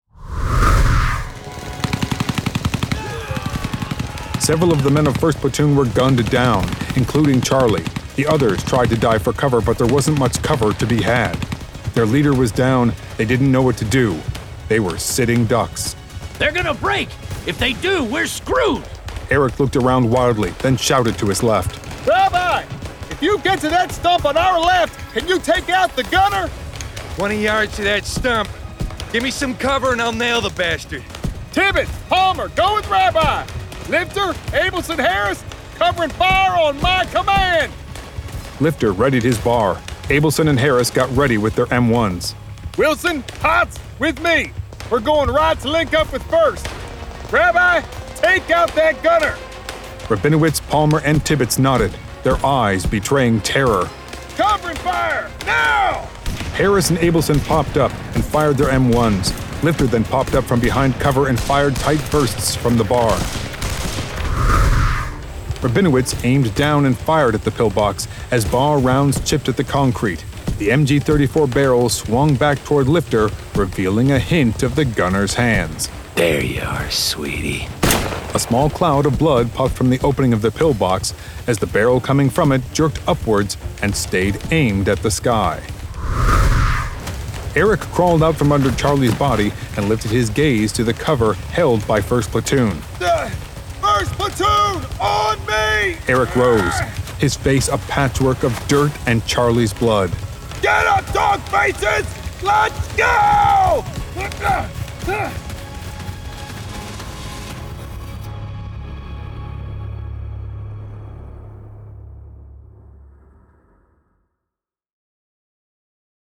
DRUDEN [Dramatized Adaptation]
Full Cast. Cinematic Music. Sound Effects.
Genre: Horror
Adapted from the screenplay written by Scott Sigler with Adrian Picardi and produced with a full cast of actors, immersive sound effects and cinematic music!